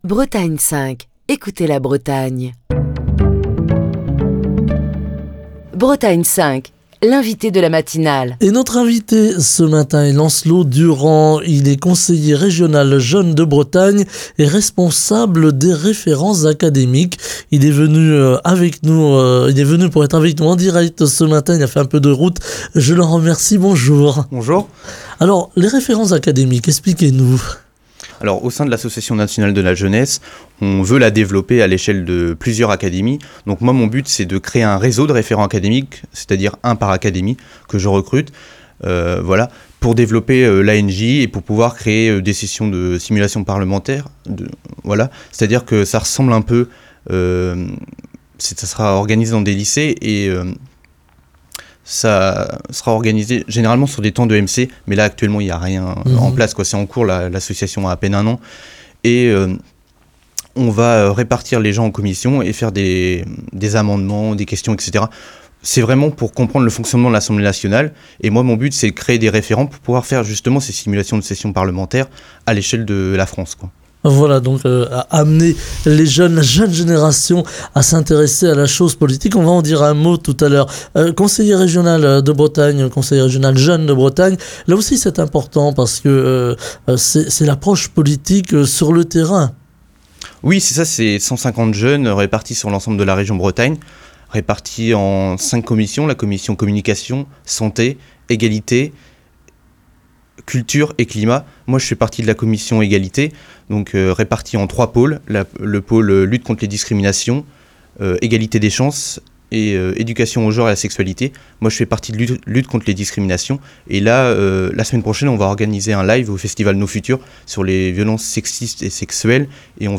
Écouter Télécharger Partager le podcast Facebook Twitter Linkedin Mail L'invité de Bretagne 5 Matin , présenté par